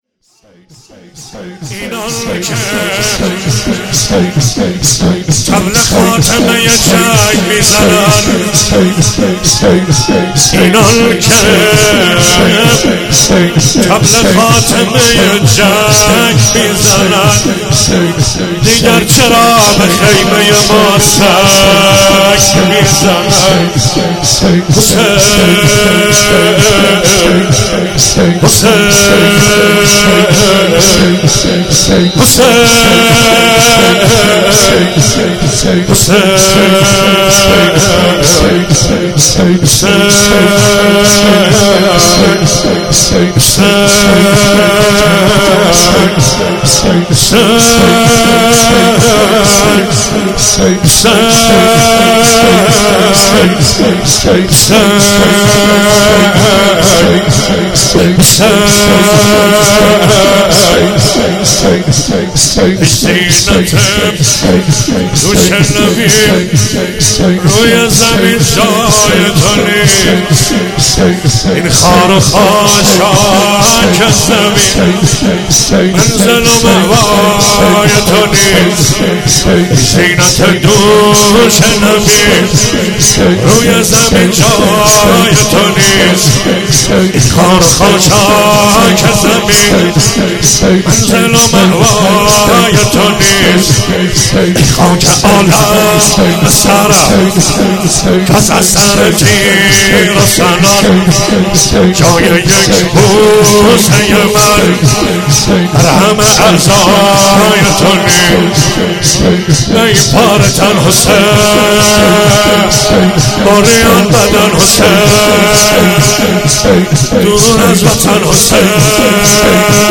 ولادت سرداران کربلا
شور مداحی